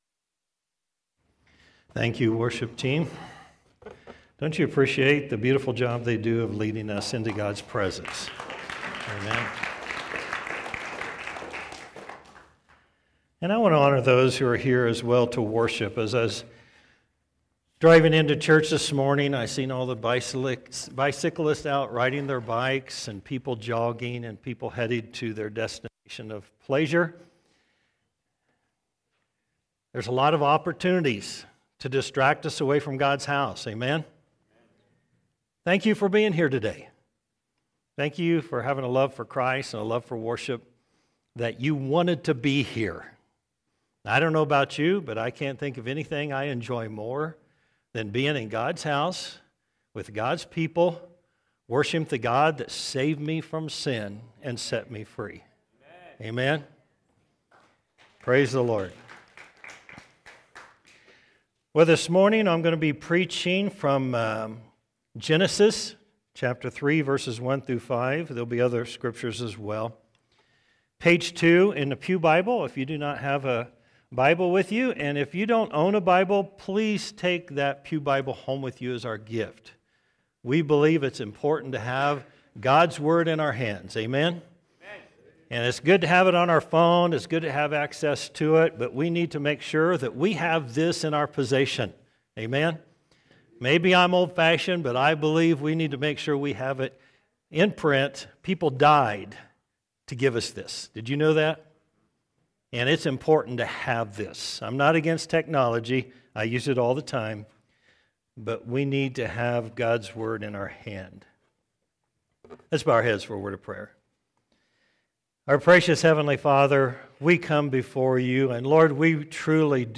Sermons | First Baptist Church of Golden
Sunday Morning Worship